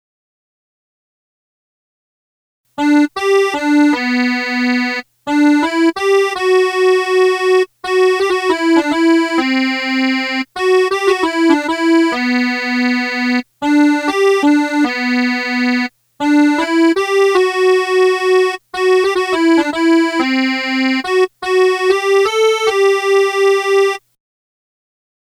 sem-valtellin-melody1.mp3